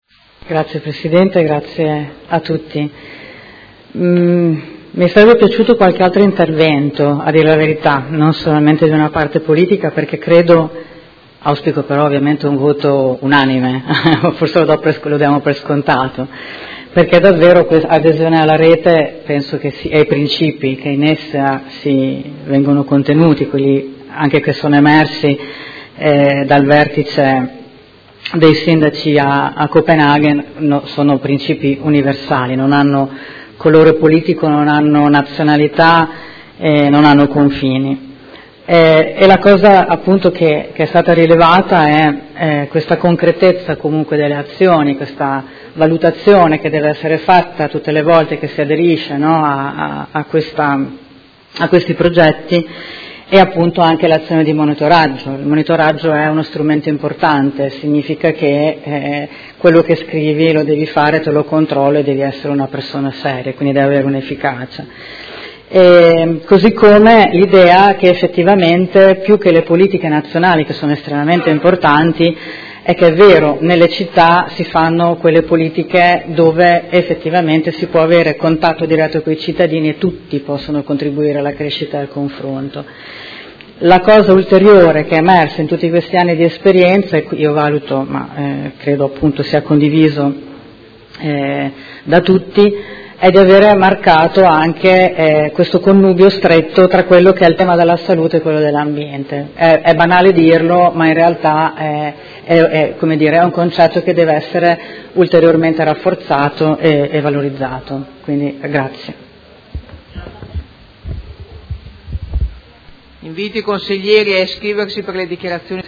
Seduta del 28/03/2019. Replica a dibattito su proposta di deliberazione: Candidatura della Città di Modena alla fase VII (2019-2024) del progetto “Città sane” proposto dall'O.M.S. - Organizzazione Mondiale della Sanità – Approvazione